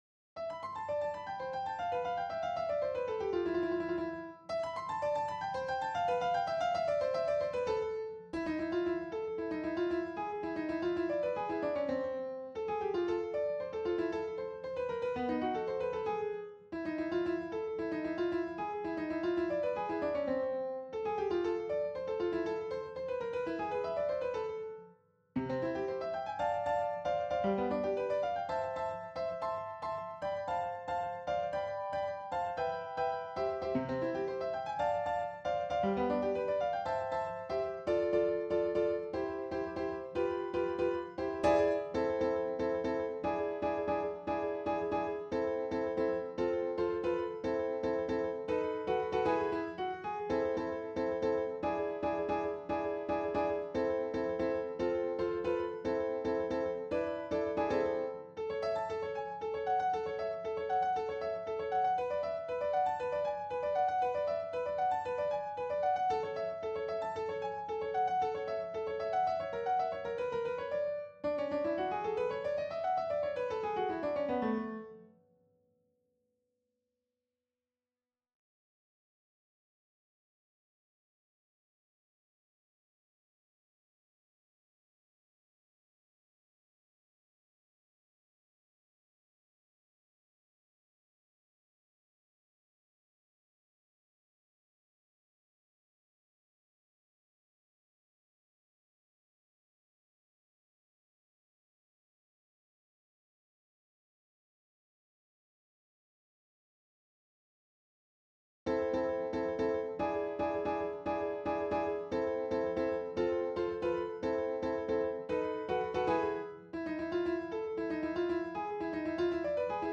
• Instruments = "Violin,Violin,Violin,Viola,Cello,Contrabass,Piano,Piano"
Tico-Tico-03-Piano_0.mp3